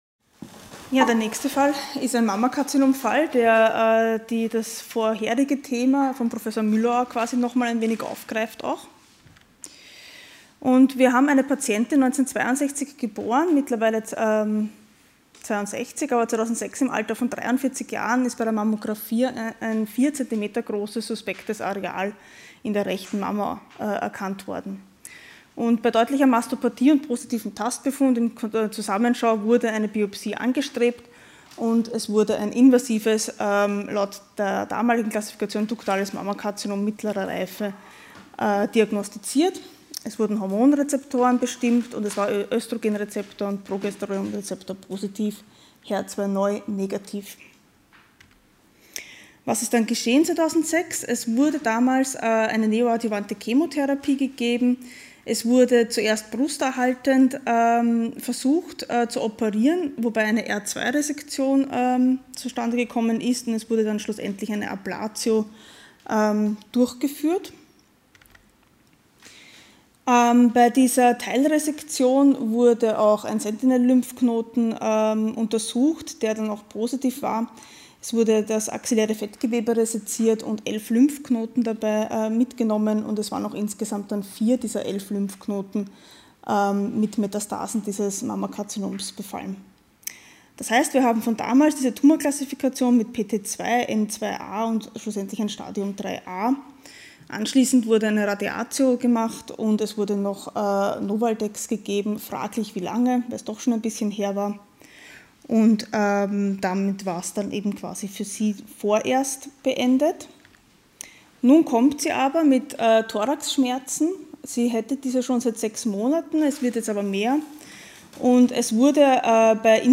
Sie haben den Vortrag noch nicht angesehen oder den Test negativ beendet.